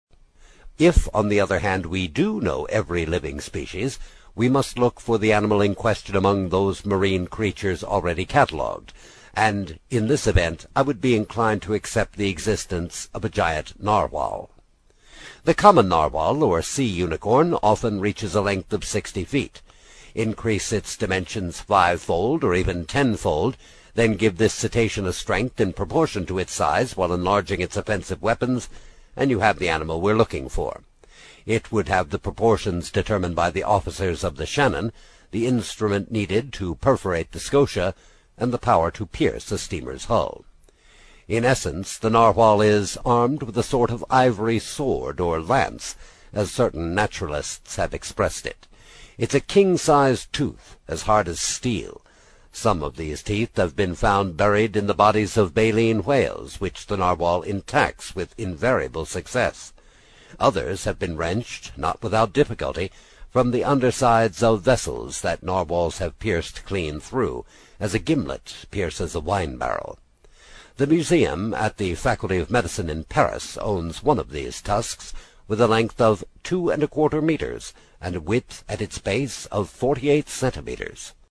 在线英语听力室英语听书《海底两万里》第17期 第2章 正与反(6)的听力文件下载,《海底两万里》中英双语有声读物附MP3下载